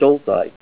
Help on Name Pronunciation: Name Pronunciation: Stolzite
Say STOLZITE Help on Synonym: Synonym: ICSD 75981   PDF 19-708